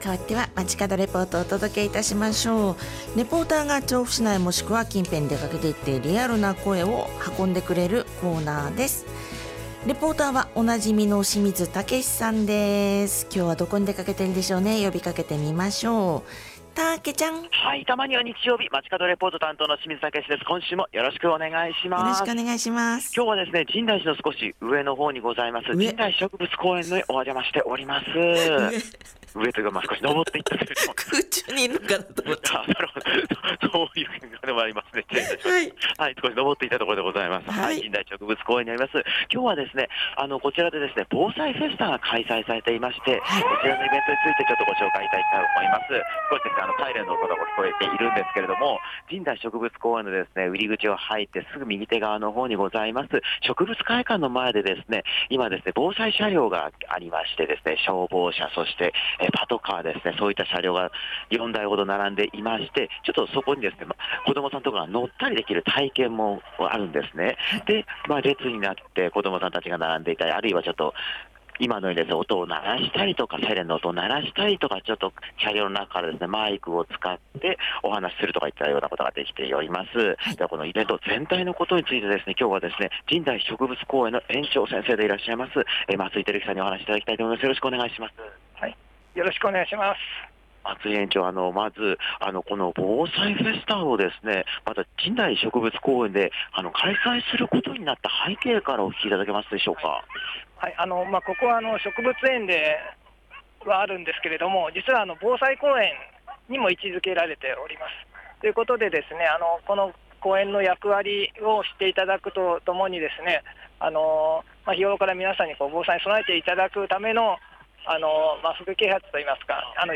今週も比較的暖かい空の下からお届けした街角レポートは、 都立神代植物公園で開催中の「防災フェスタ」からお届けしました！
レポート中に体験者の方がサイレンを鳴らしている様子の音が入ったかと思いますが、 警察車両、消防車両、白バイなど、各車両に列ができるほど人気の様子でした。